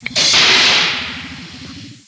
Door Opening
dooropen.wav